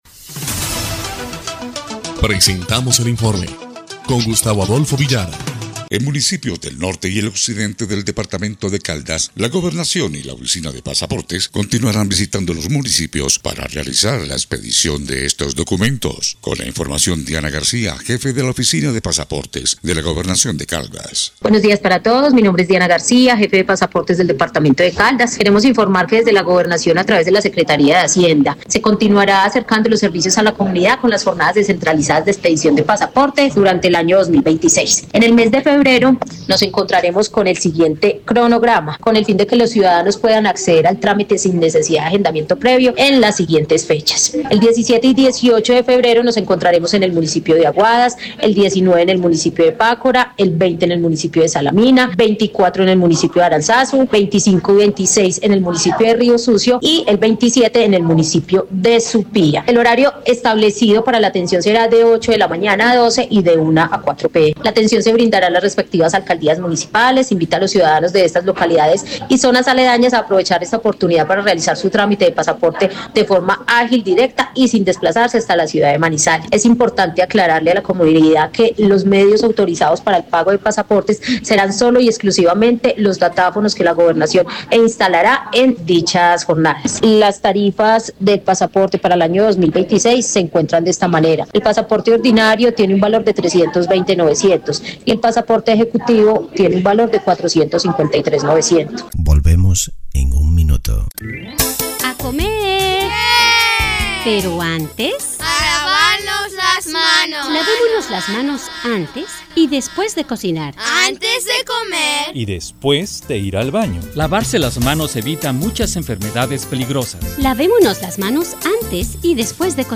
EL INFORME 3° Clip de Noticias del 11 de febrero de 2026